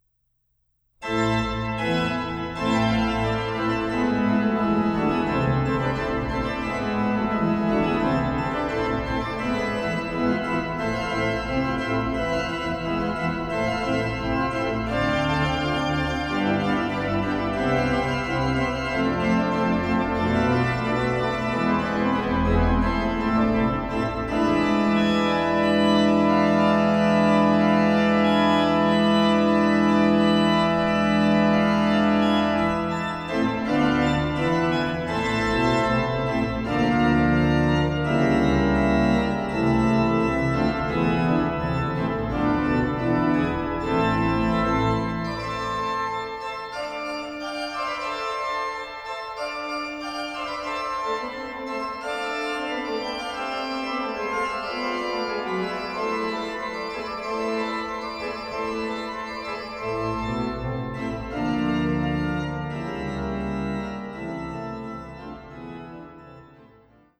concerti transcrits pour l’orgue